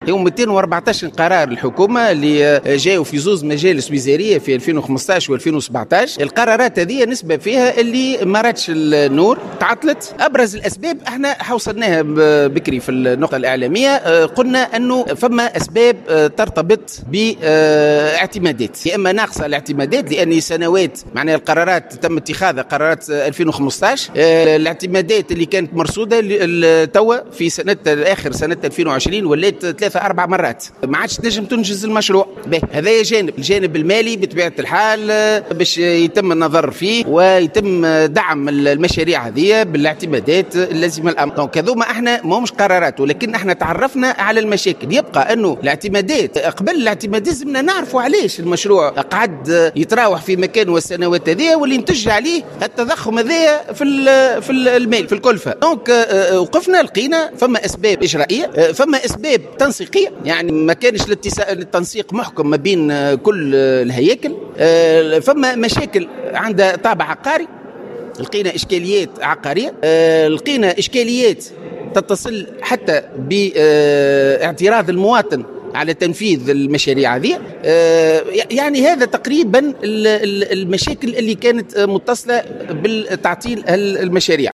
أكد أحمد سليمان منسق الوفد الحكومي الذي أدى زيارة إلى ولاية القيروان خلال ندوة صحفية اليوم الأربعاء أن عدد القرارات التي اتخذت لفائدة القيروان في مجلسين وزاريين سنتي 2015 و2017 يبلغ 214 قرارا .